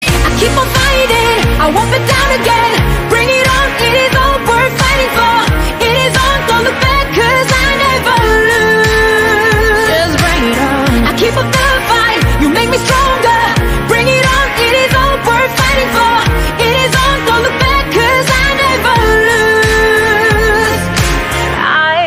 • Качество: 320, Stereo
поп
громкие
женский голос
Саундтрек к корейскому сериалу